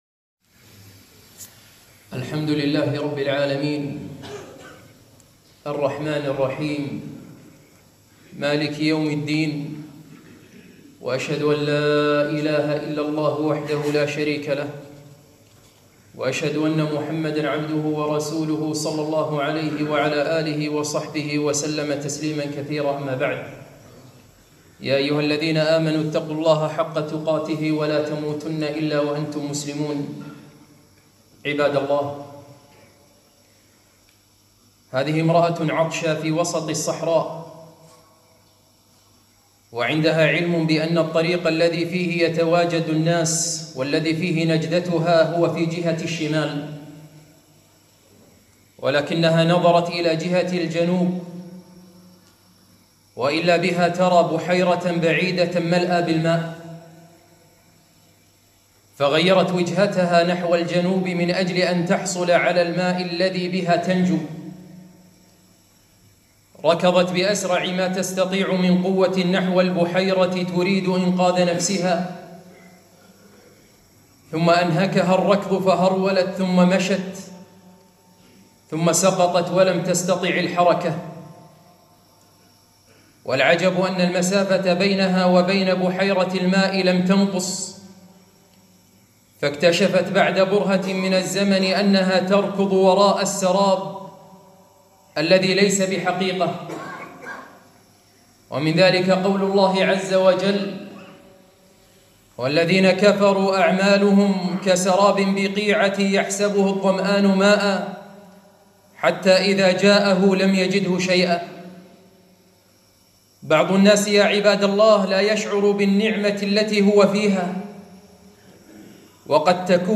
خطبة - أريد أن أهرب من منزل أبي